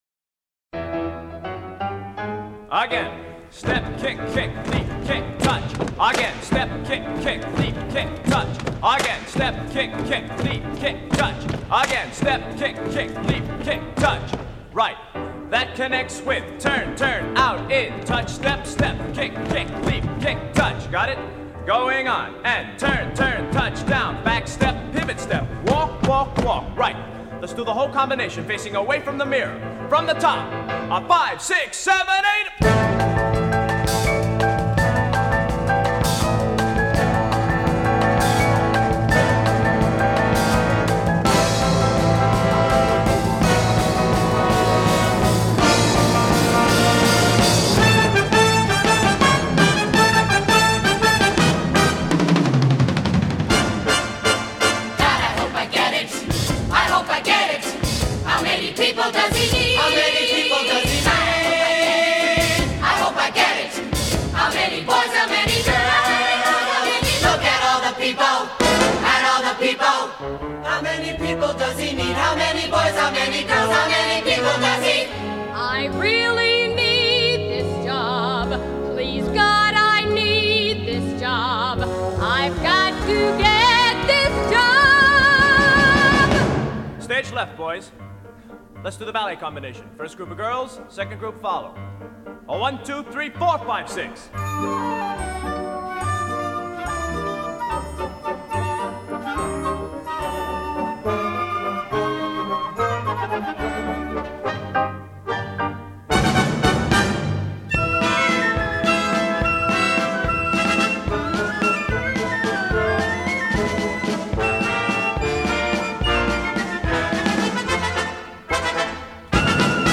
1975   Genre: Musical   Artist